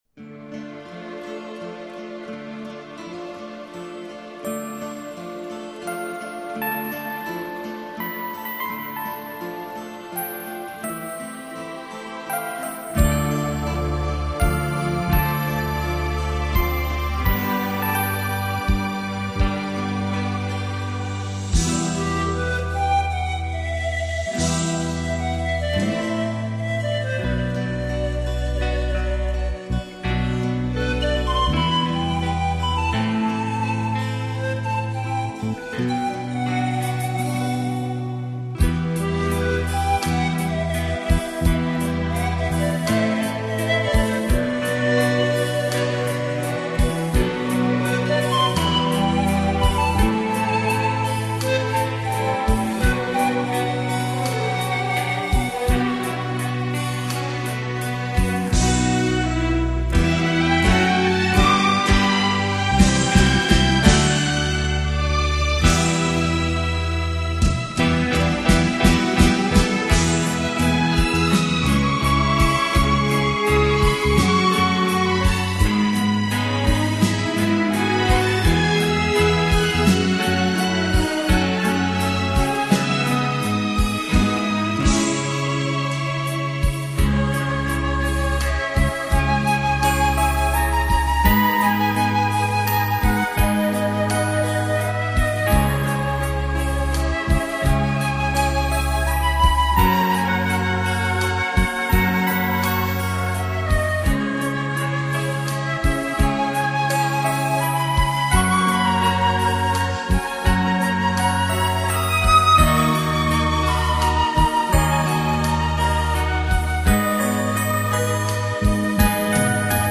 主奏: 排萧  萨克斯管  钢琴
詹姆斯·拉斯特乐队演奏风格
这张CD音色纯净宽广，旋律优美
清新华丽的音乐，算来已有十年的历史